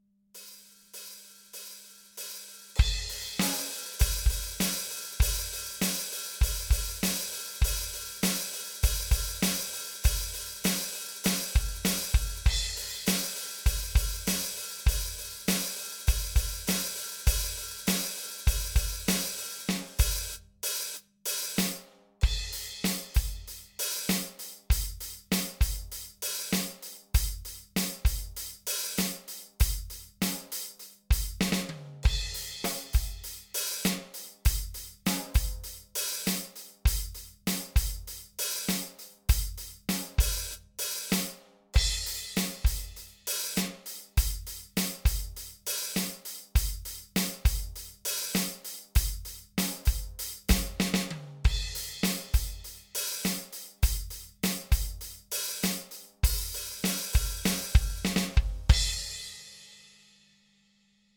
パソコンに取り込むことができるかテストしてみた。 取り込み結果は上々、腕前は下々。 出だしがばらついているし、バスドラが遅れている。全体的に軽快感がなく、後ろに引っ張られている。いっぱいいっぱいな感じ全開だ。